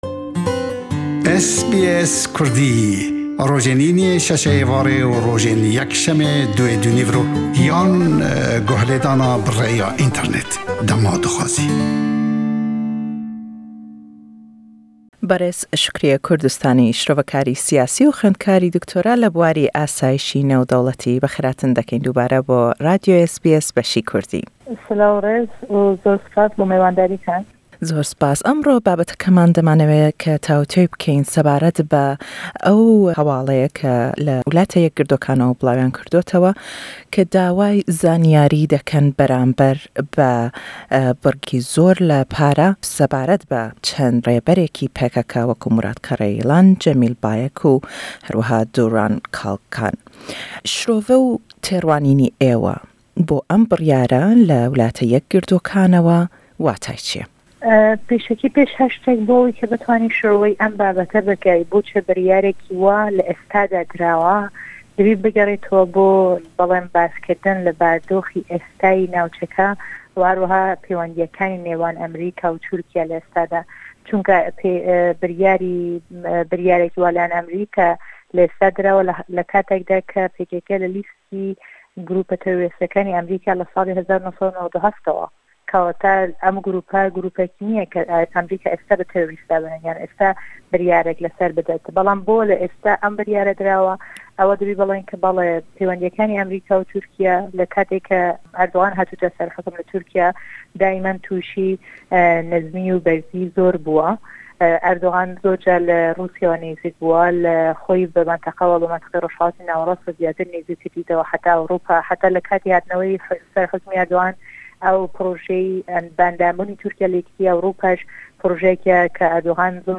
Şirove: Emrîka çend ciddîye le terxan kirdinî mîlyonan dolar bo kokirdinewey zanîyarî sebaret be sê serkirdey PKK?